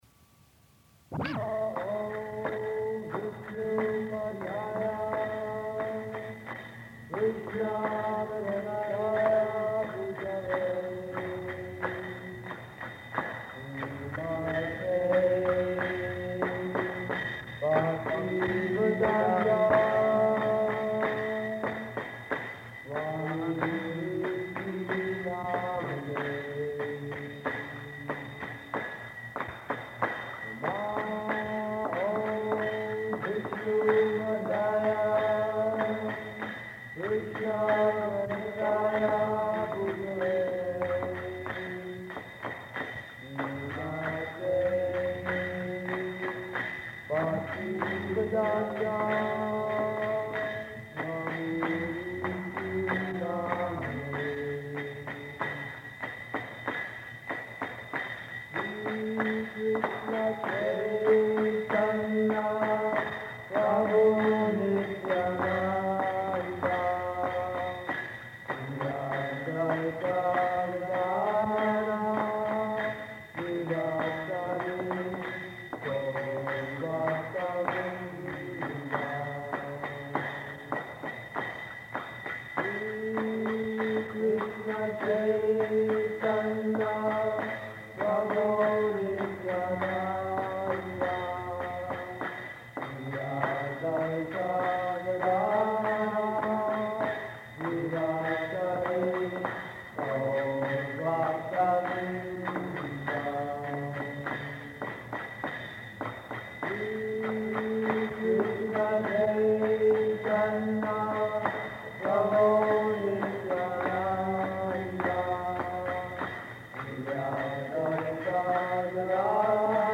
Engagement Lecture at Boston College
Type: Lectures and Addresses